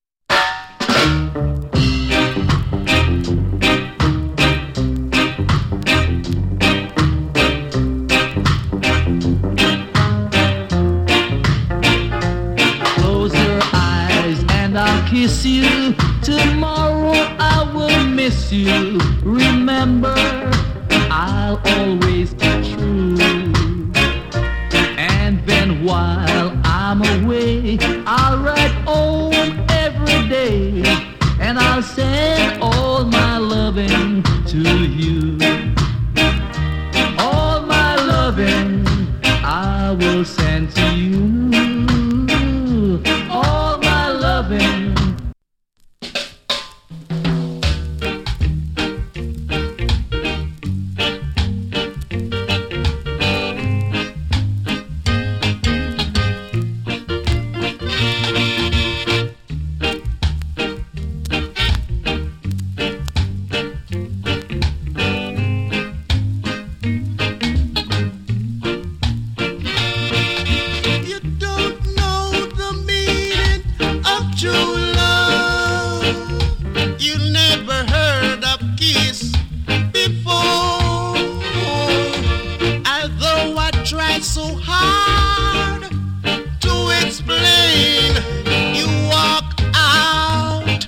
GREAT ROCK STEADY !